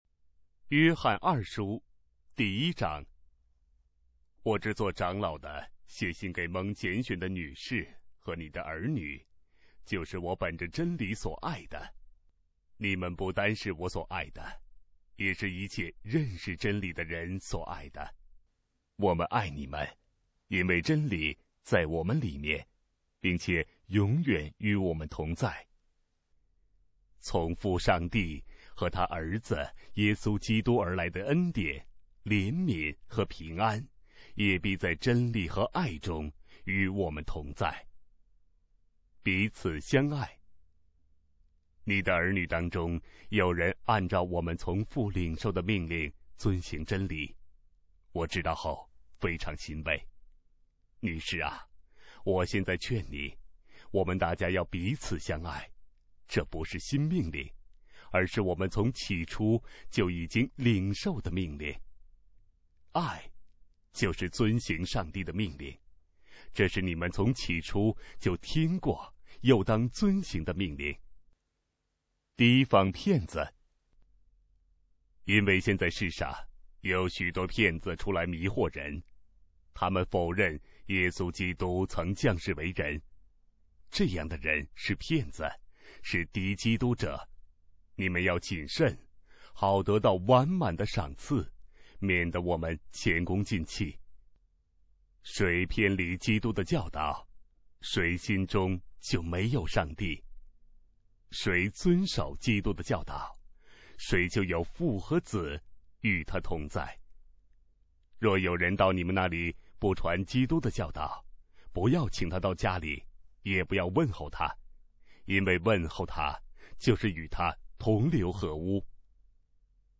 当代译本朗读：约翰二书